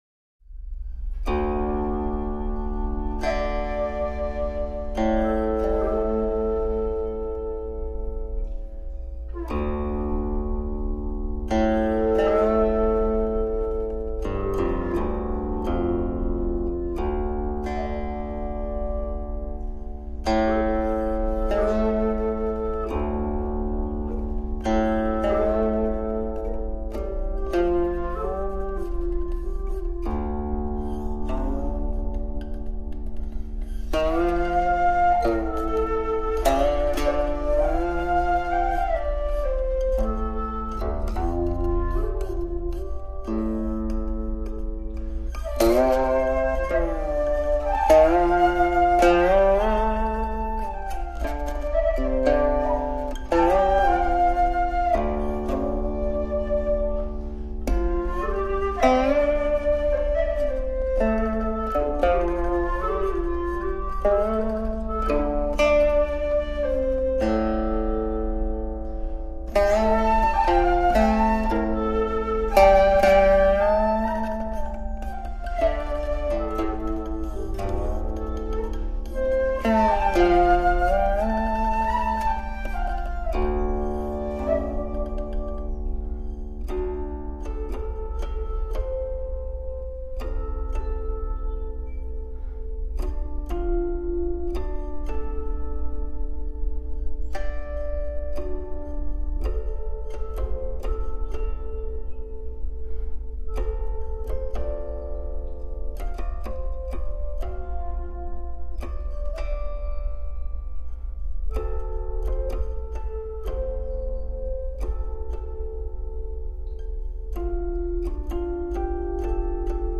而簫聲悠遠，不礙琴韻，復善移人之情，爰有斯作，以奉知音。